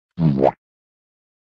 spongebob-boowomp.ogg